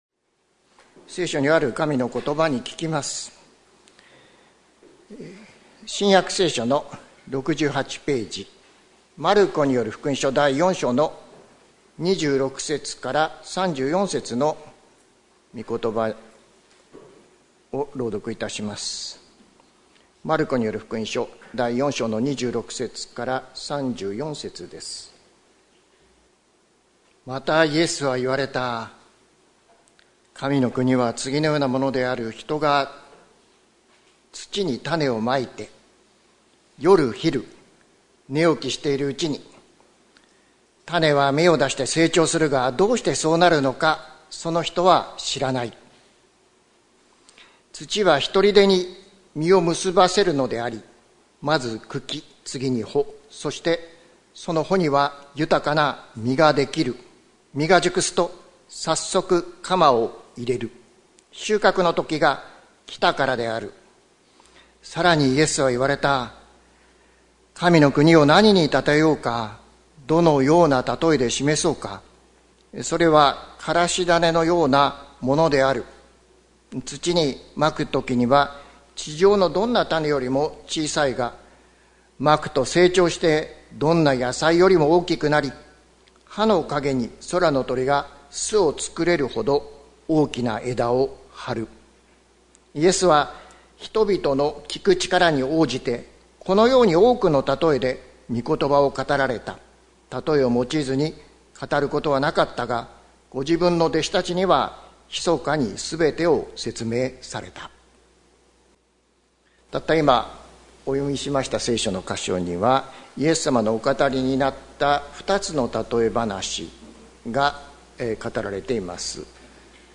2025年08月17日朝の礼拝「いのちの種の未来」関キリスト教会
説教アーカイブ。